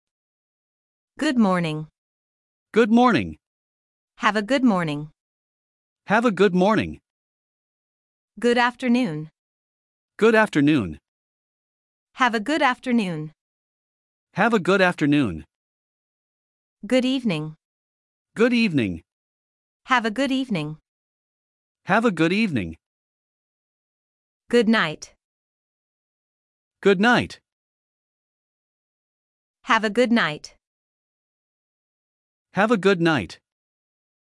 Comment prononce-t-on 'Good evening'?
Voici un Mp3 avec toutes ces expressions prononcées par des anglophones.